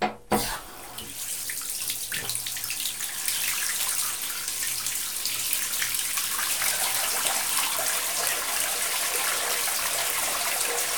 bath1.ogg